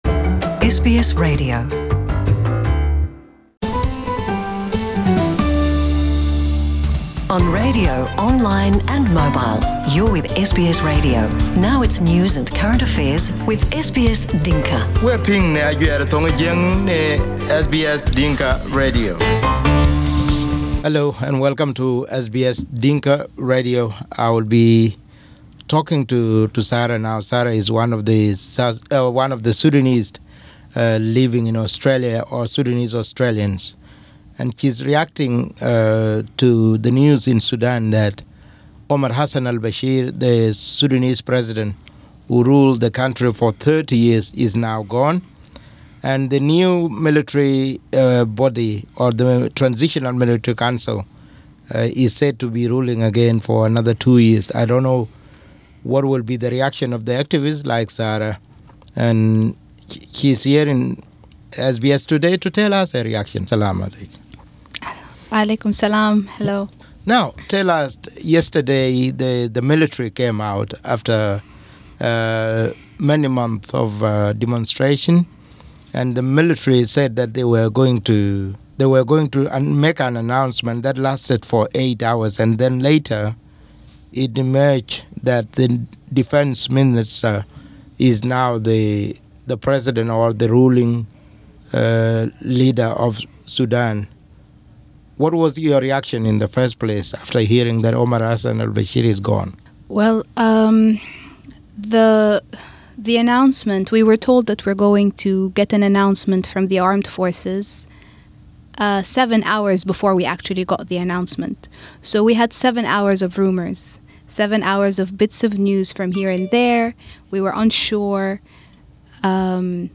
The group press release said that the Sudanese community in Australia and diaspora don’t recognise Awad Ibn Auf. In this Interview